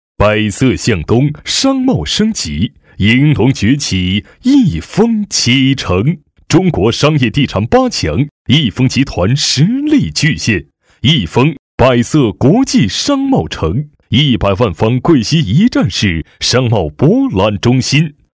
亿丰房产配音男341号（气
大气浑厚 建筑房产